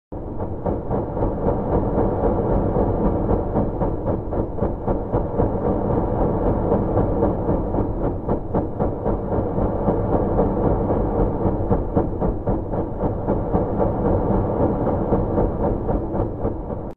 El sonido captado de PSR 1919+21
El sonido repetitivo y rítmico que emite el púlsar PSR B1919+21 proviene de la rotación de la estrella de neutrones que lo forma, combinado con la emisión de pulsos de radiación electromagnética.
Cuando la rotación del púlsar orienta estos haces hacia la Tierra, se percibe un pulso de radiación en intervalos muy regulares, creando el “tic-tac” que escuchamos en grabaciones de sus emisiones de radio. PSR B1919+21, por ejemplo, tiene un periodo de rotación de aproximadamente 1.337 segundos entre pulsos.